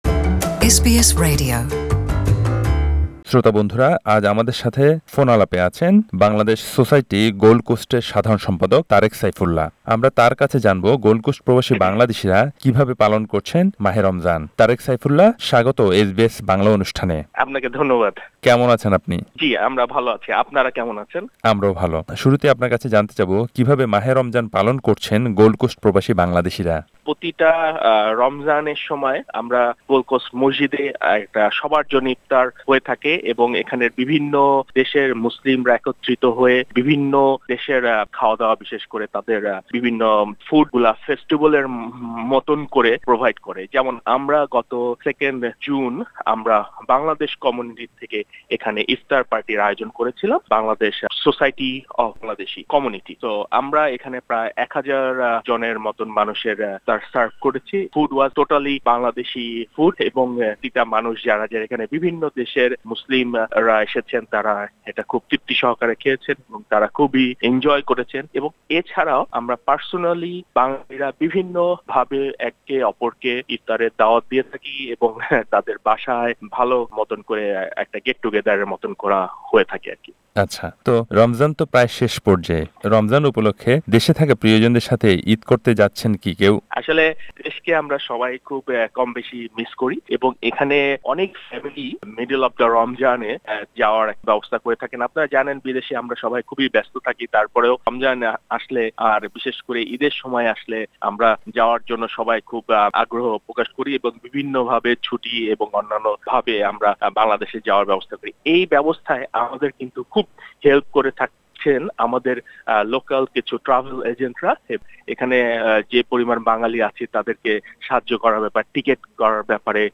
পুরো কথোপকথন শুনতে অডিও লিঙ্কে ক্লিক করুন।